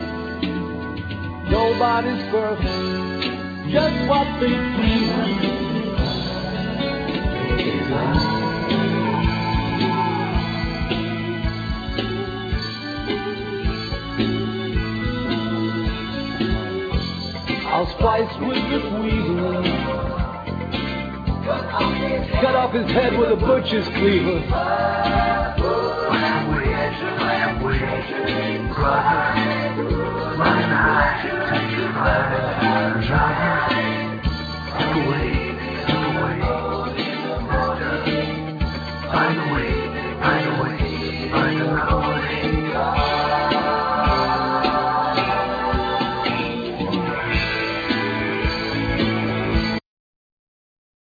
Vocal,Piano,Samples
Guitar,Banjo,Trombone
Drums
Double bass
Tenor saxophone
Accordion
Violin